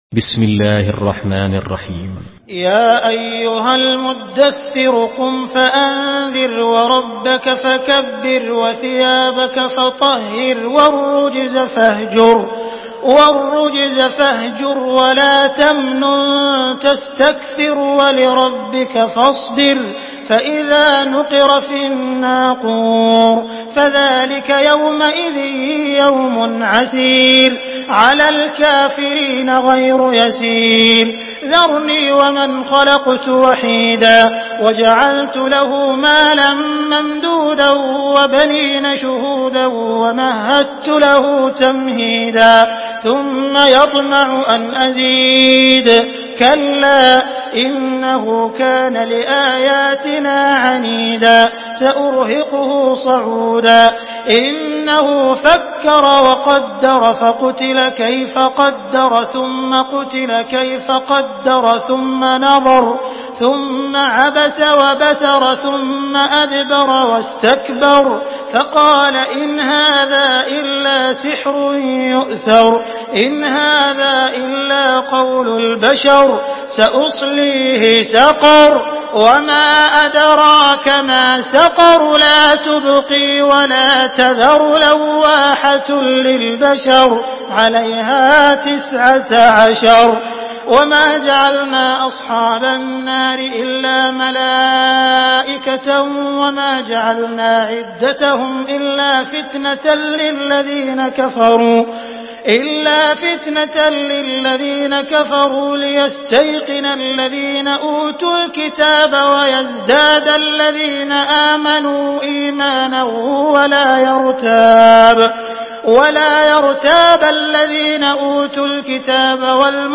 Surah Müdahhir MP3 by Abdul Rahman Al Sudais in Hafs An Asim narration.
Murattal Hafs An Asim